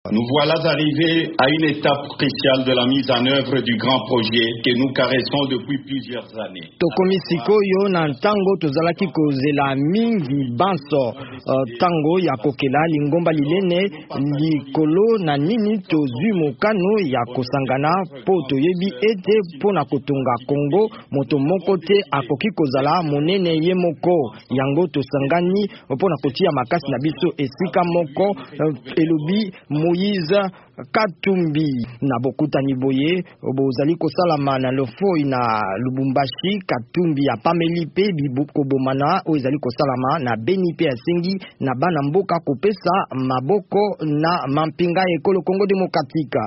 Lisikulu ya Katumbi na bokutani ya Ensemble na Lubumbashi (na Lingala)
Moïse Katumbi mokambi ya Ensemble alobi ete lingomba lizali kokanisa ndenge nini kobongwana na parti politiki. Alobi yango na bokutani ya Lofoi, na Lubumbashi, Haut-Katanga.